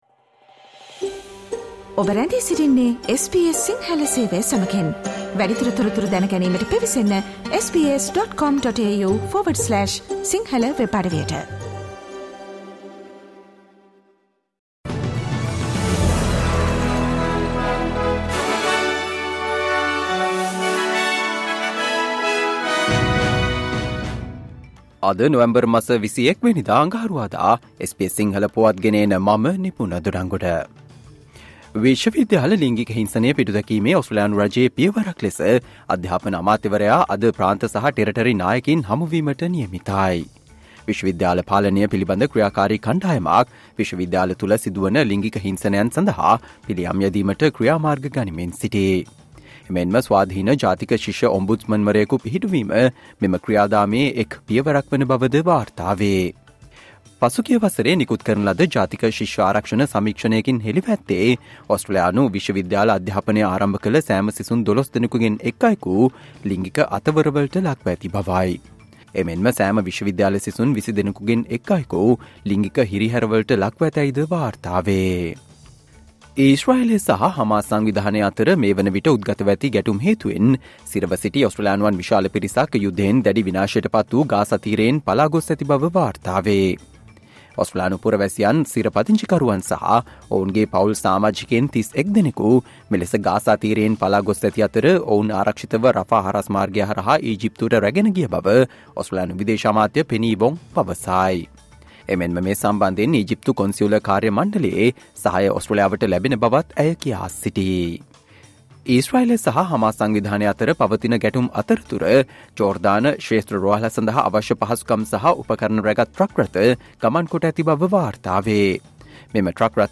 ඕස්ට්‍රේලියාවේ පුවත් සිංහලෙන්, විදෙස් සහ ක්‍රීඩා පුවත් කෙටියෙන්.